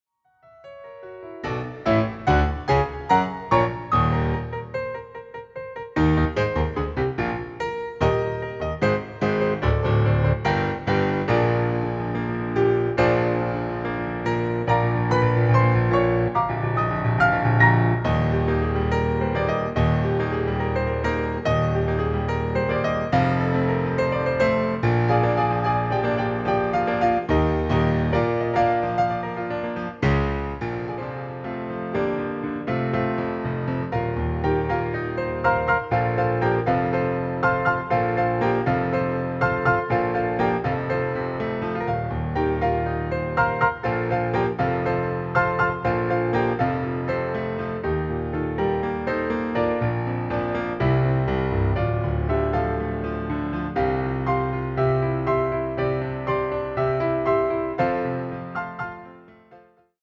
piano versions
reimagined with a more relaxed tone